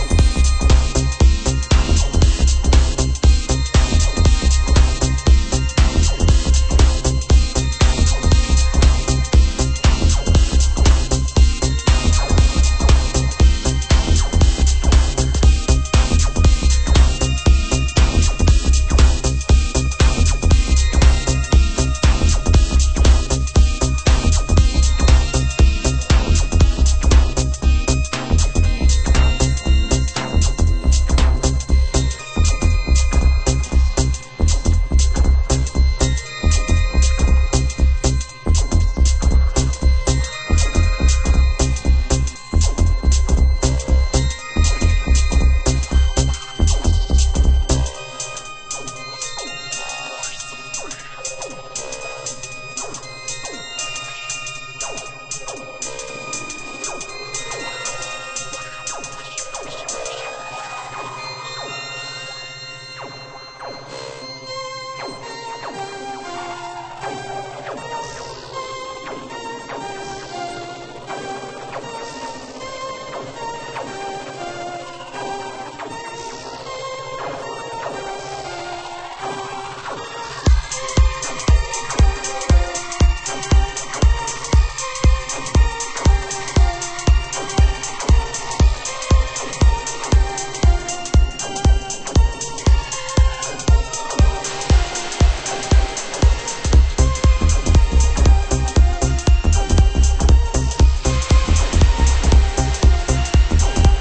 DISCODUB